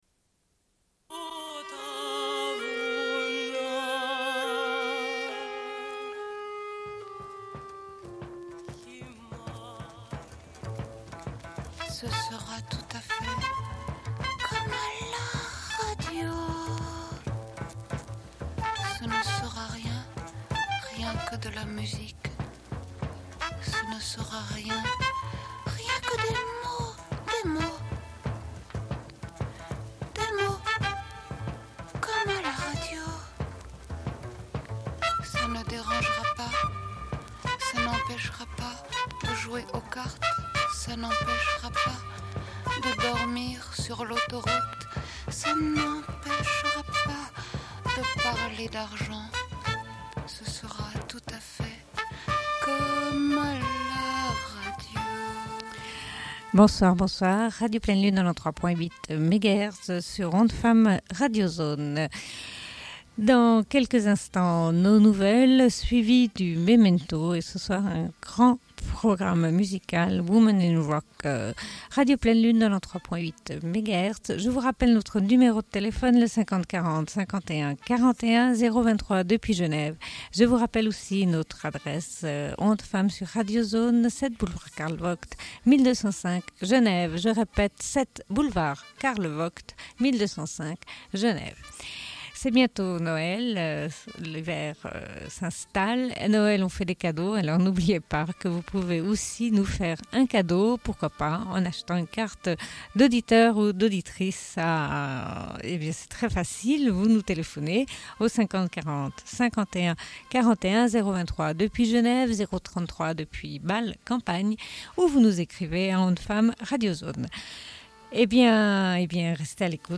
Bulletin d'information de Radio Pleine Lune du 20.10.1993 - Archives contestataires
Une cassette audio, face B31:20